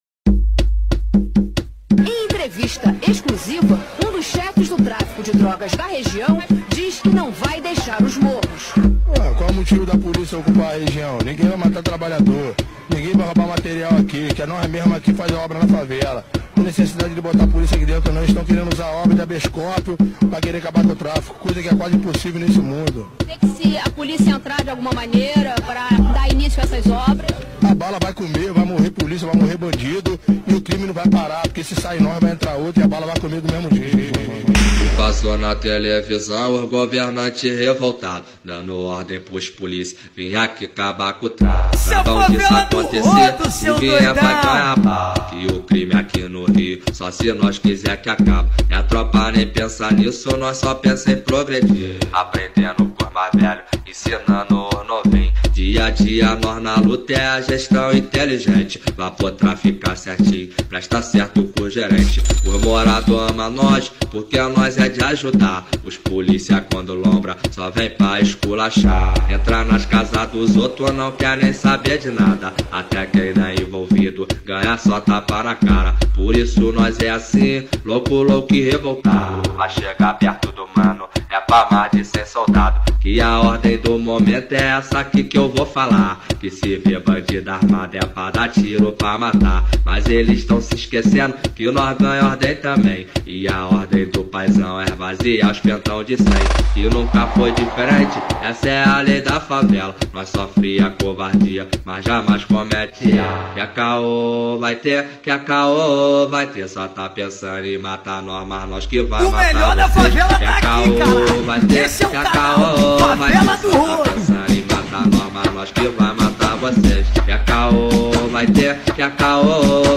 2024-02-14 21:30:02 Gênero: Funk Views